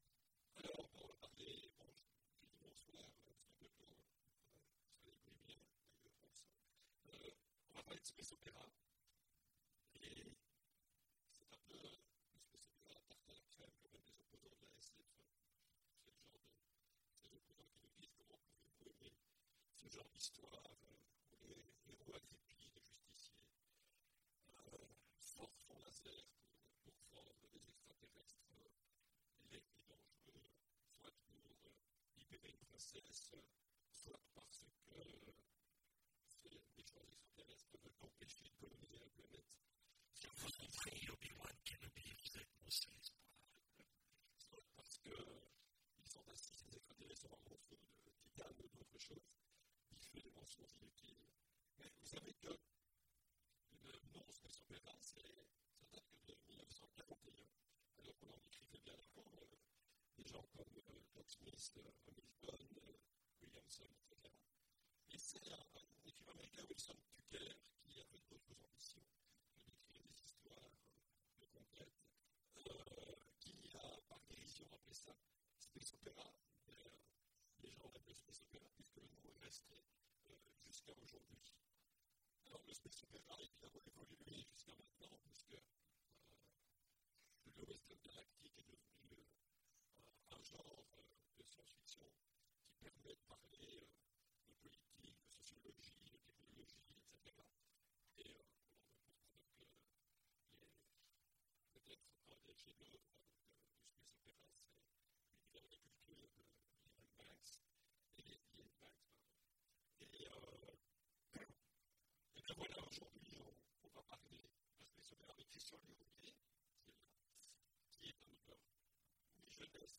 Imaginales 2016 : Conférence Space opera, planet opera…